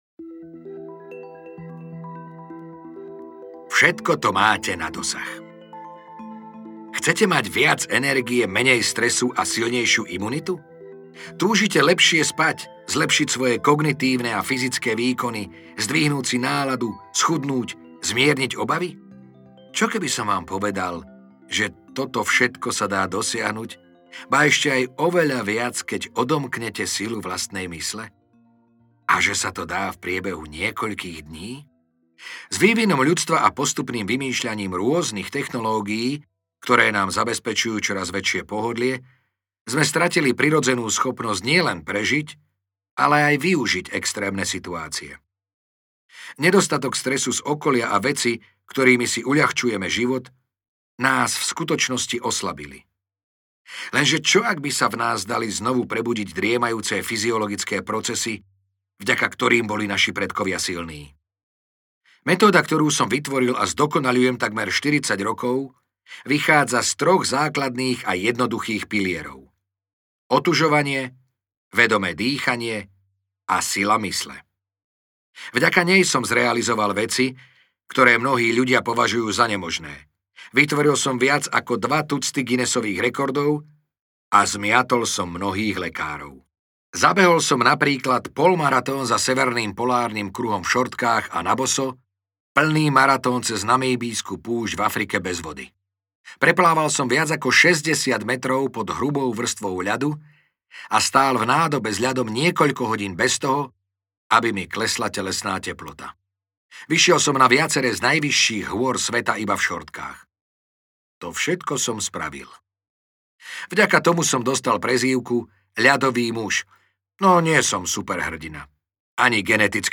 Metóda Wima Hofa audiokniha
Ukázka z knihy